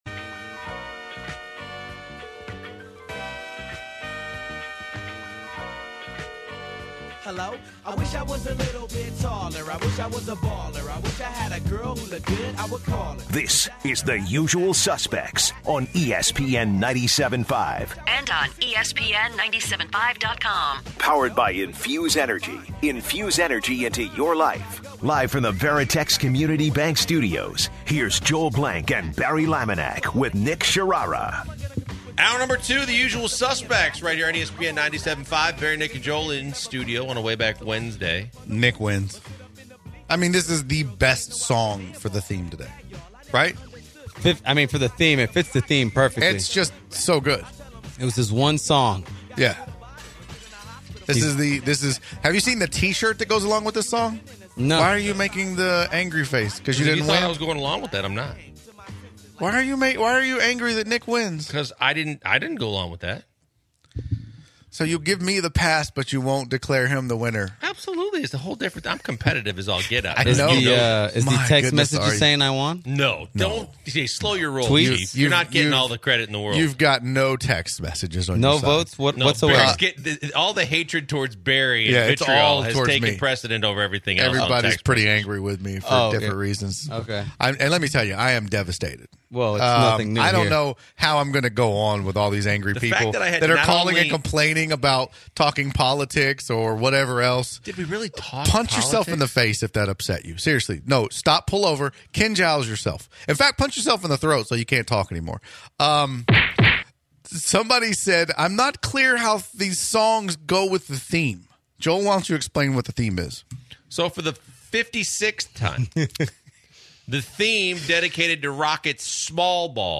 This comes after a political discussion in hour one. The guys have a debate on the different types of playing fields such as UH TDECU stadium vs. Lambeau fields. The next debate is veganism.